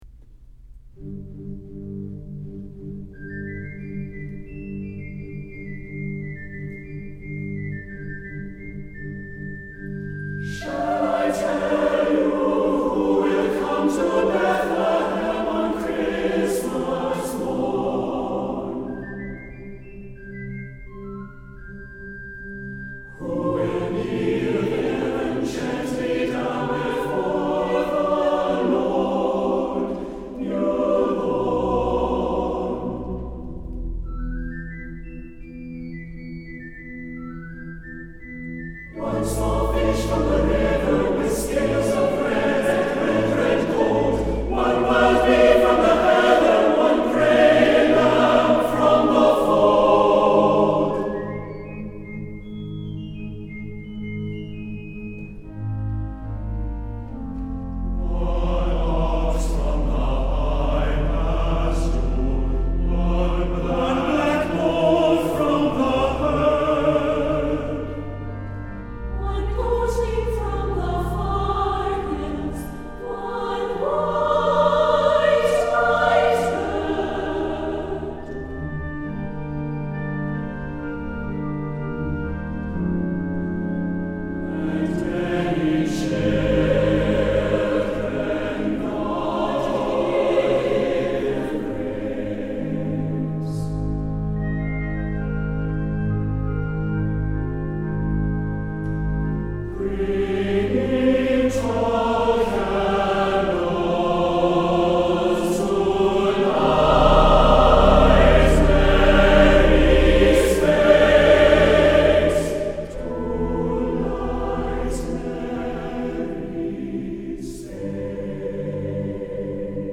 choral recording